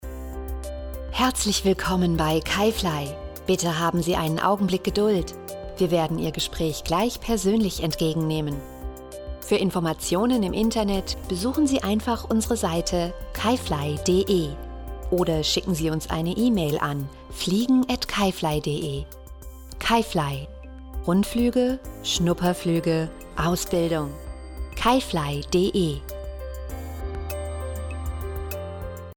warme Stimmfarbe, Stimmalter 25-45, 25 Jahre Theatererfahrung
Kein Dialekt
Sprechprobe: Industrie (Muttersprache):